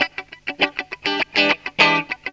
GTR 72 EM.wav